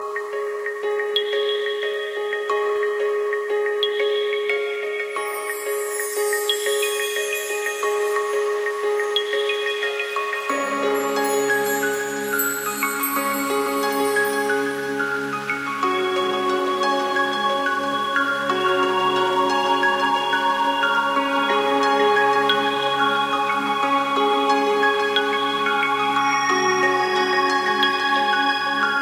Categoria Allarmi